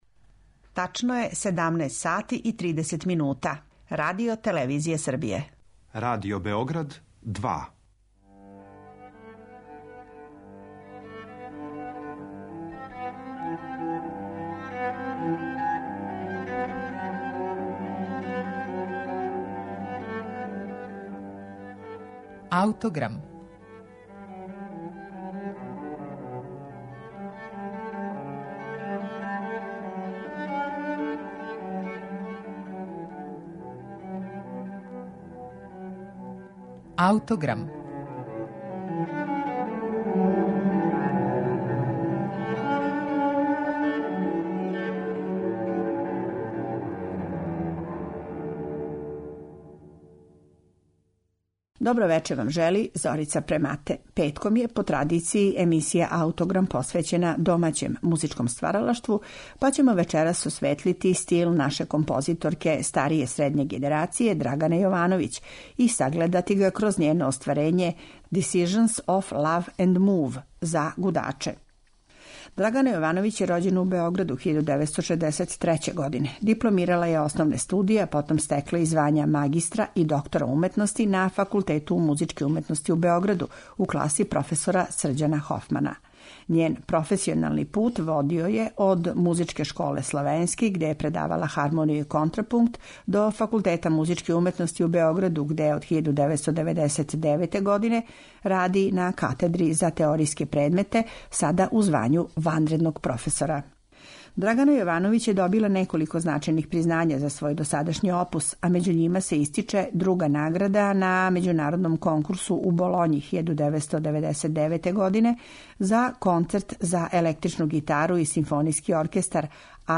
Композицију на нашем снимку свира гудачки корпус СО РТС-a